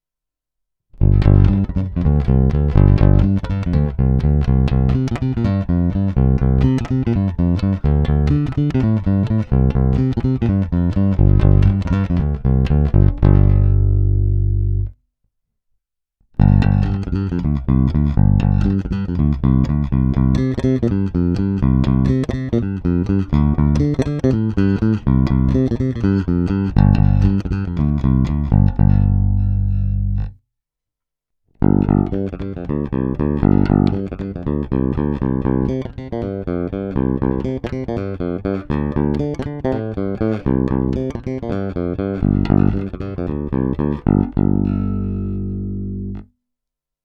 Zvukově je to naprosto klasický a opravdu skvělý Jazz Bass.
Není-li uvedeno jinak, následující nahrávky jsou provedeny rovnou do zvukové karty a bez stažené tónové clony.